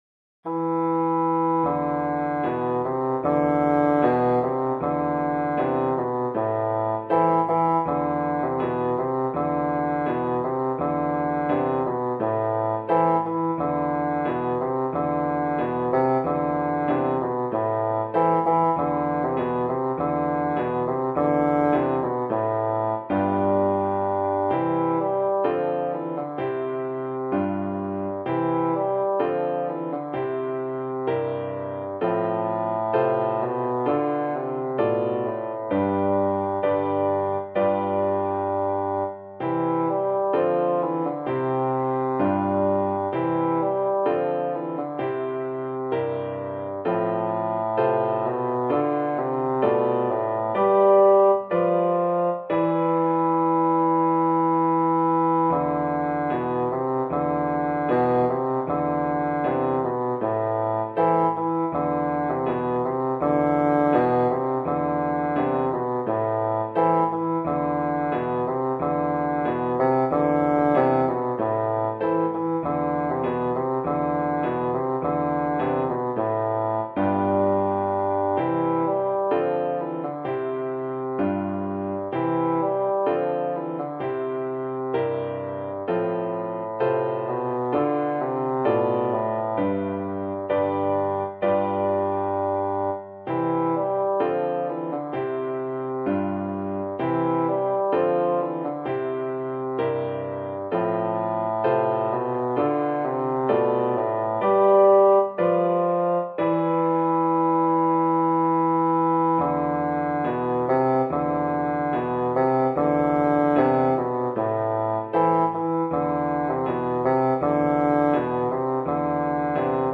traditional Russian Folk Song